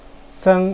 seng1.wav